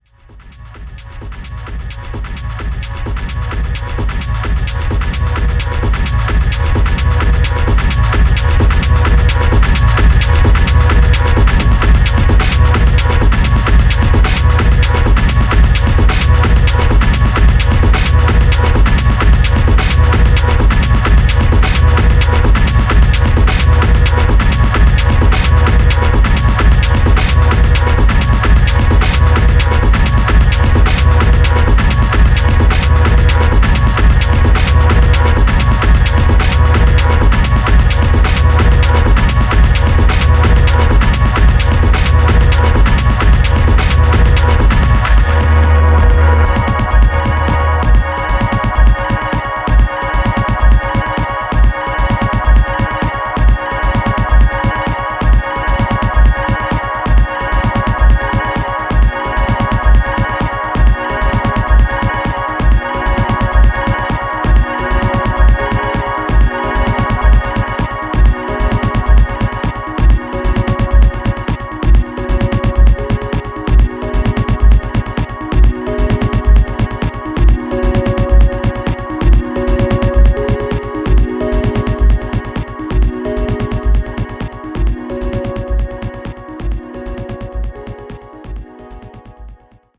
pushes the boundaries of techno to another level